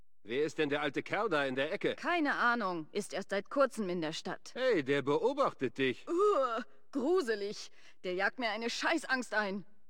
Fallout: Brotherhood of Steel: Audiodialoge
FOBOS-Dialog-Carbon-Bürger-007.ogg